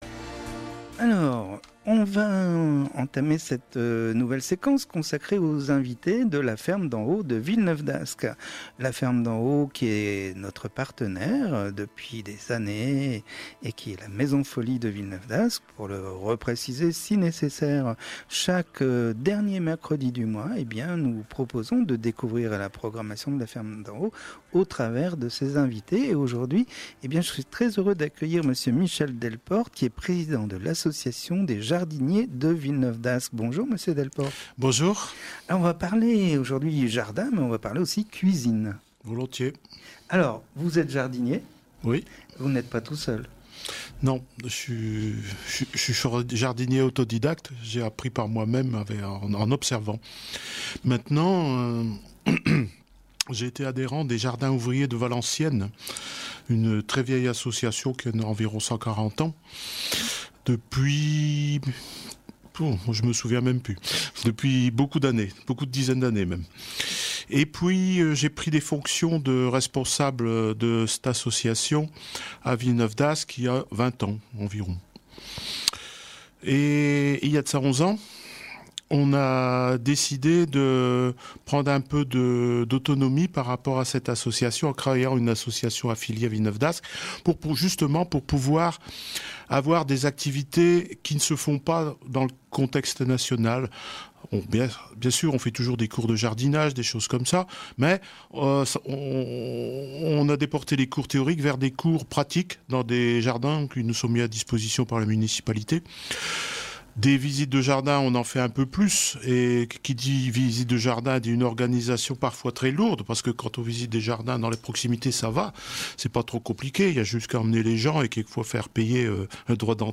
Radio Campus, 26 avril 2017 Entretien